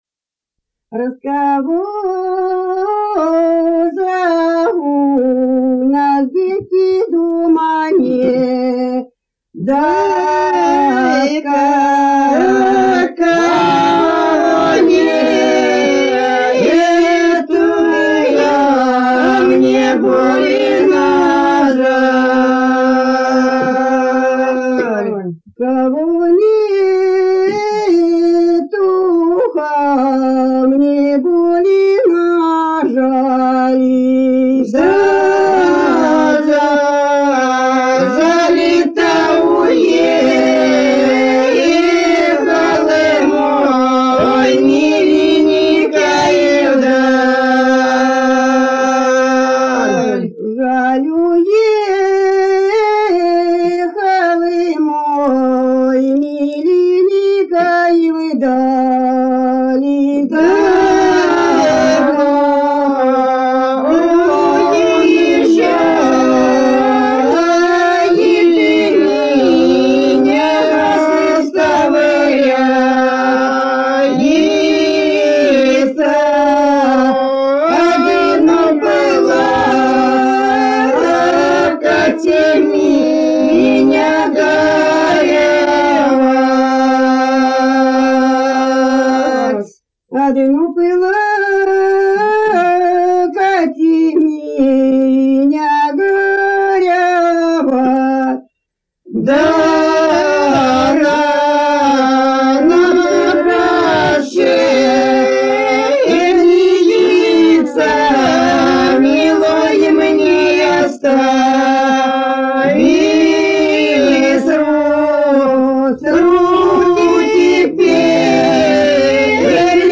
Народные песни Касимовского района Рязанской области 13. Раского же у нас, девки, дома нет — лирическая.